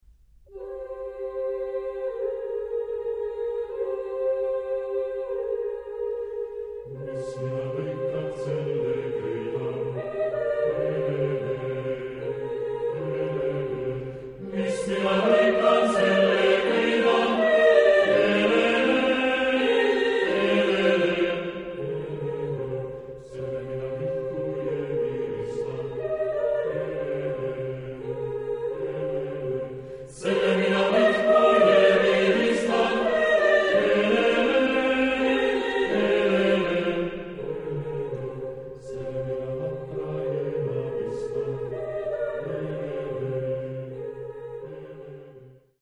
Genre-Style-Forme : Arrangement choral ; Folklore ; Profane
Type de choeur : SATB  (4 voix mixtes )
Tonalité : do mineur